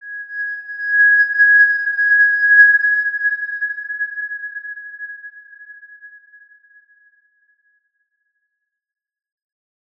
X_Windwistle-G#5-ff.wav